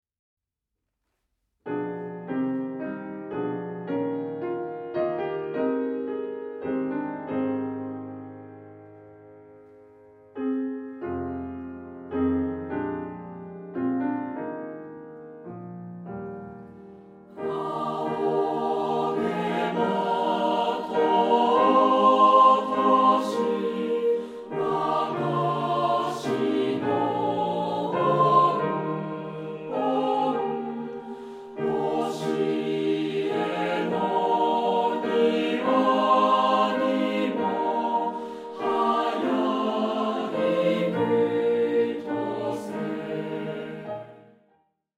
混声4部合唱／伴奏：ピアノ